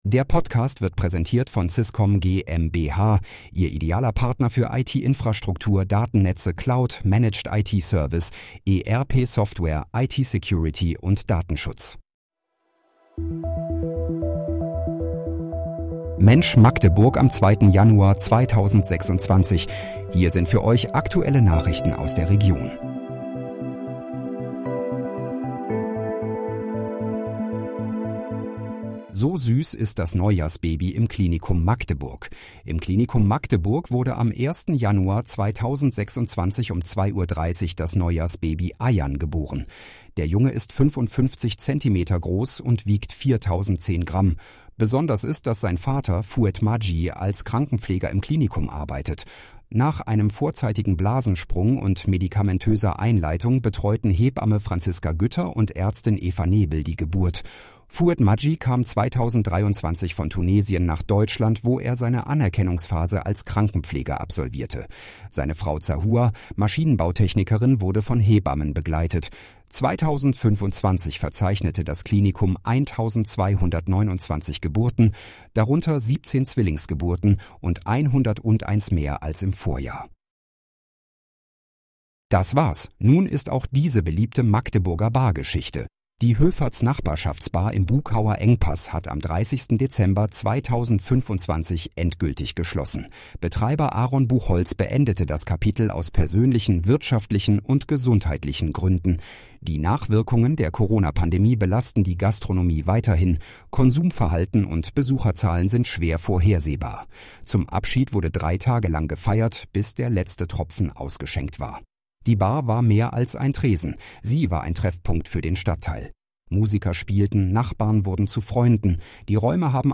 Mensch, Magdeburg: Aktuelle Nachrichten vom 02.01.2026, erstellt mit KI-Unterstützung